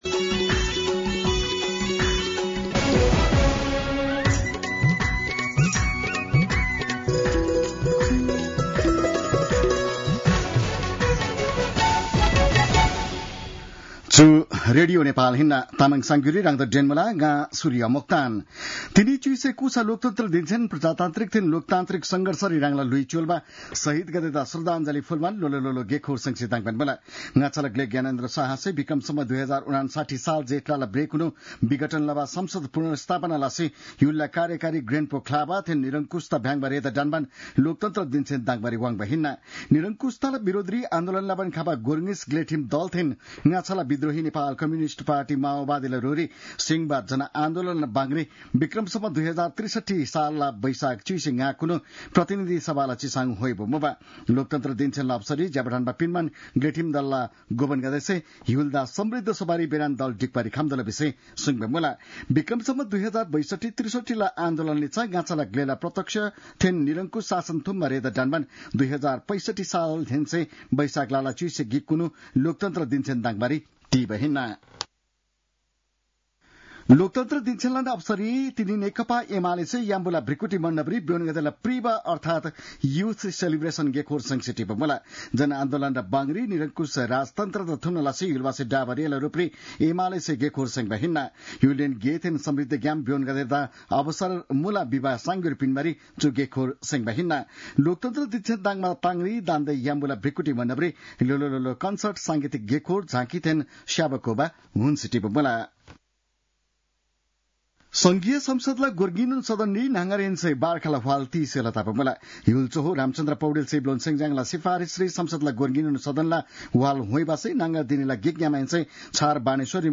तामाङ भाषाको समाचार : ११ वैशाख , २०८२
5.5-pm-tamang-news-2.mp3